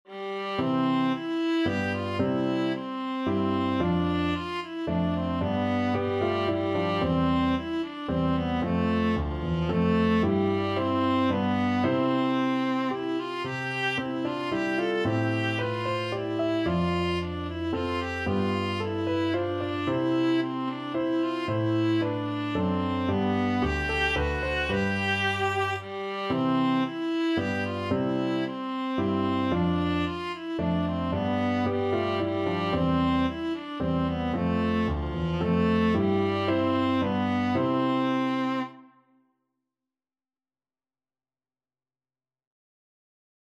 Viola
Traditional Music of unknown author.
C major (Sounding Pitch) (View more C major Music for Viola )
Moderately Fast ( = c. 112)
3/4 (View more 3/4 Music)
F4-A5